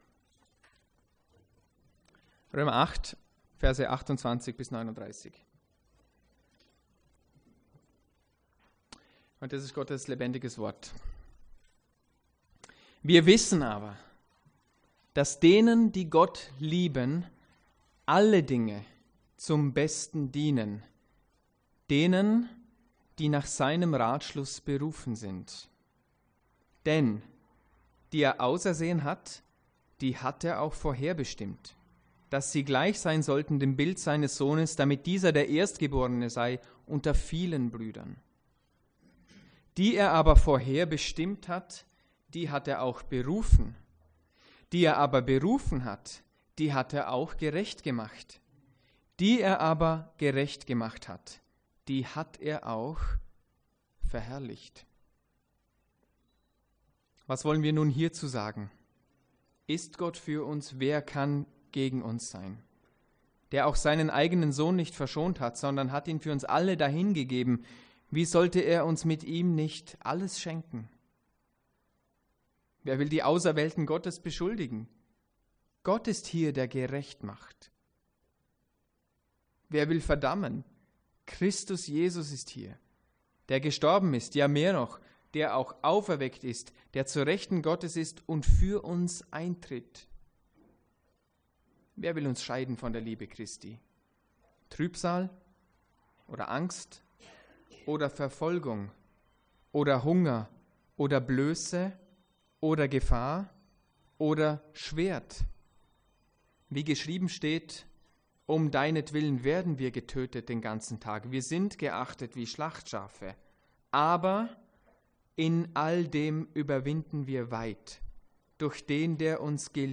Wichtige Parallelstellen zur Predigt: Psalm 23,6; Johannes 5,39-40; Johannes 6,44.65; Epheser 2,4-9